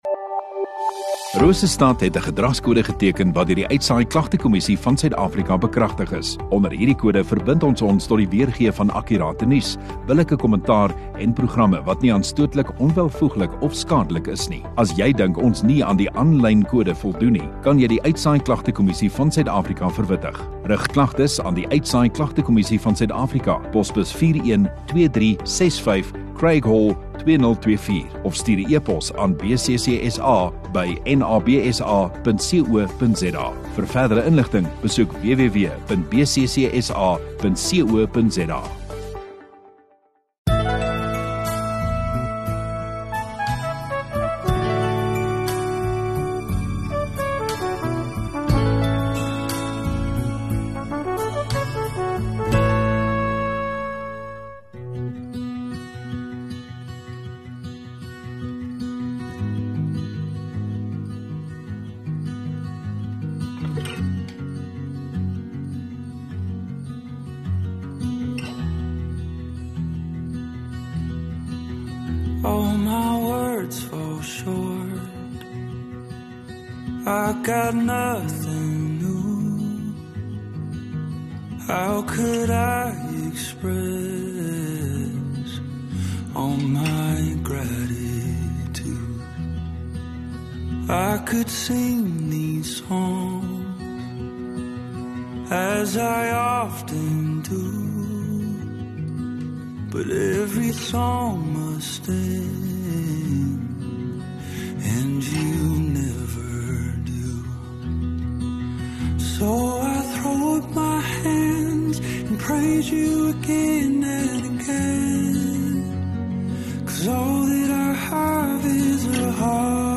21 Apr Maandag Oggenddiens